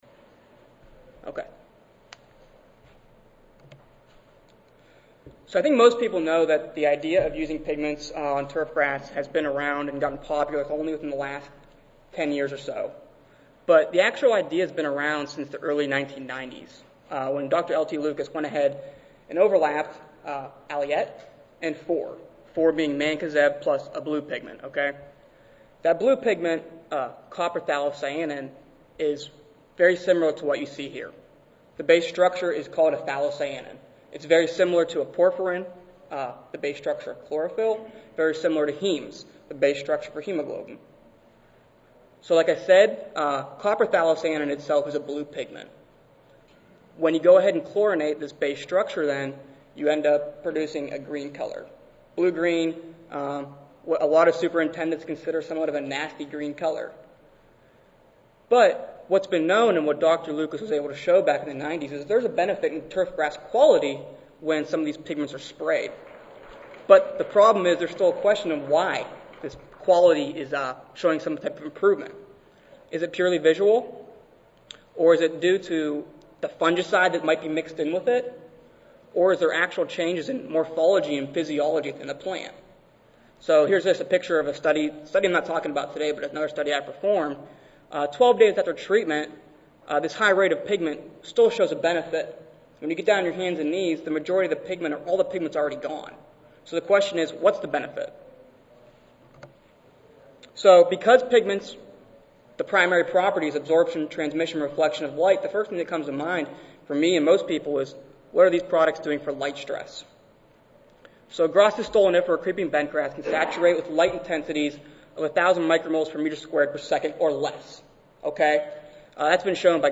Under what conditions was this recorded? C05 Turfgrass Science Session: Graduate Student Oral Competition: Weeds, Diseases, and Growth Regulation (ASA, CSSA and SSSA International Annual Meetings)